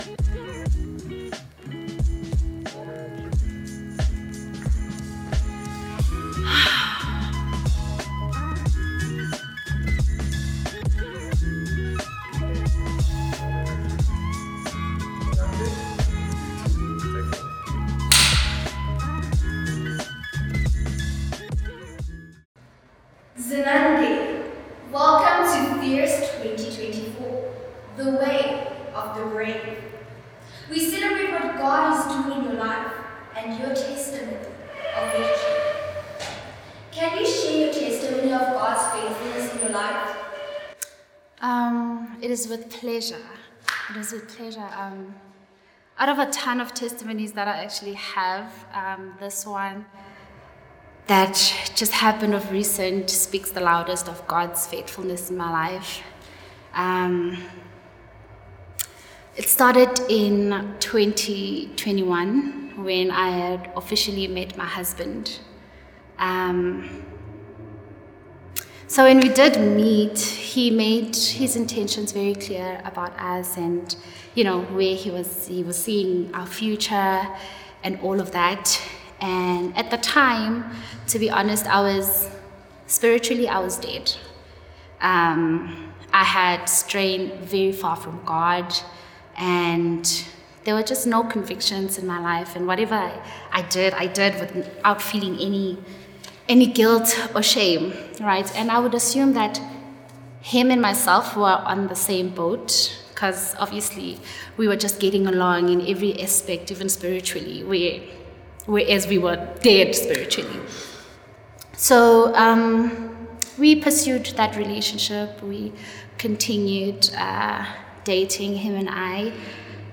The Brave Testimony: A Christian Journey of Courage Welcome to The Brave Testimony, a Christian platform where women share their powerful stories of faith and courage.